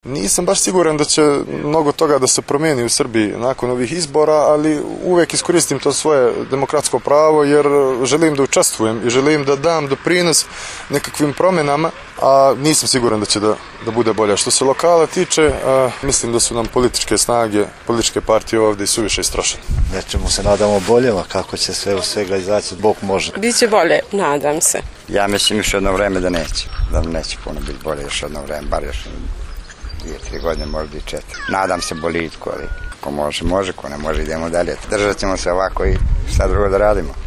Anketa: Građani Novog Pazara o izbornim očekivanjima